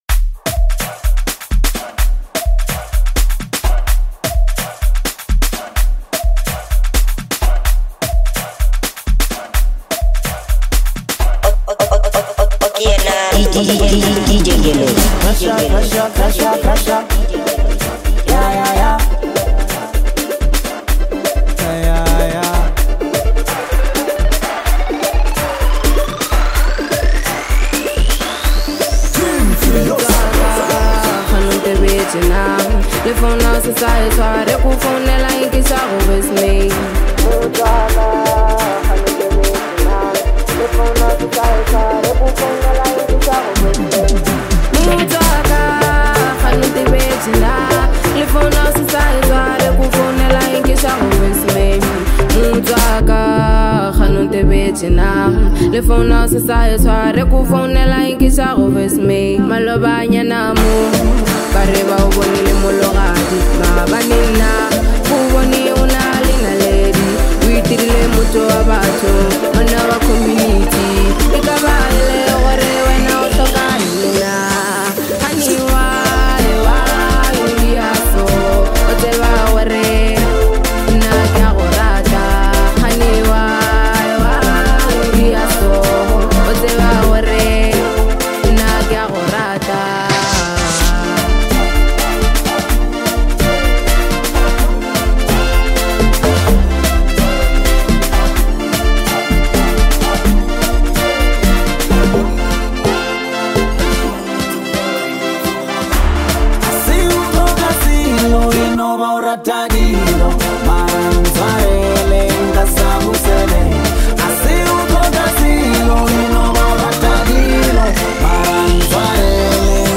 soulful vocals with deep piano rhythms